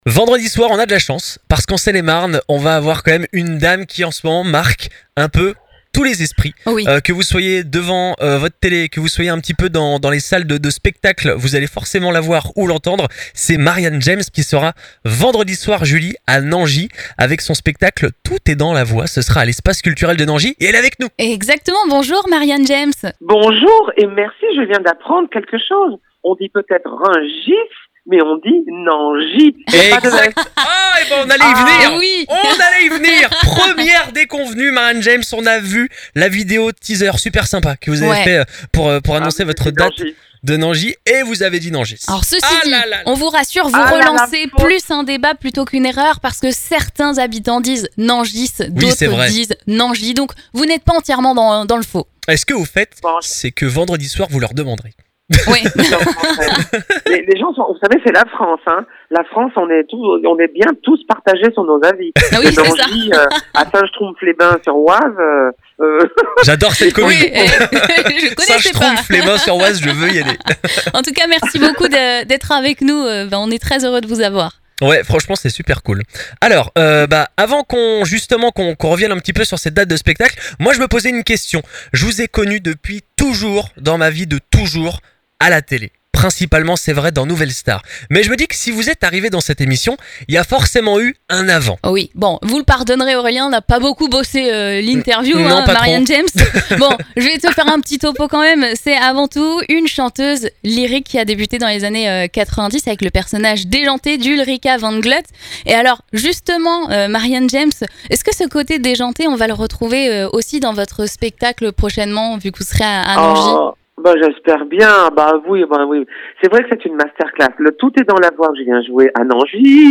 Interview de Marianne James avec les 2 du Matin !